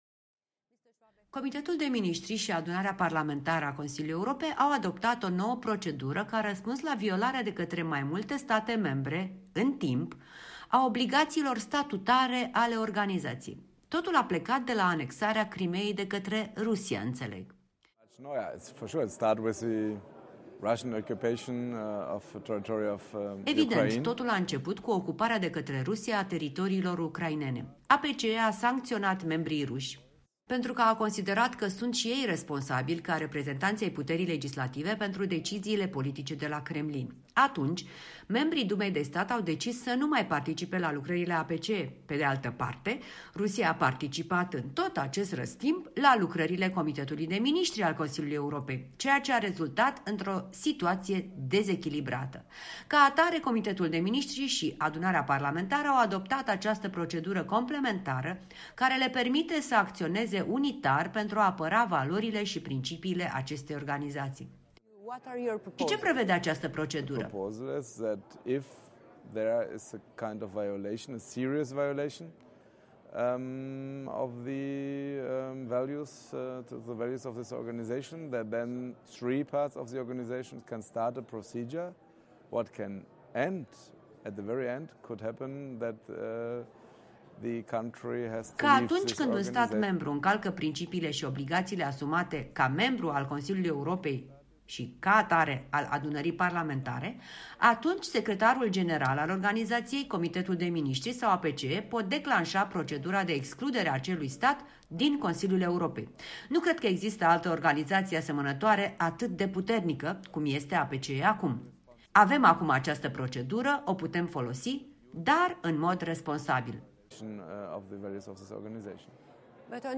Interviu cu Frank Schwabe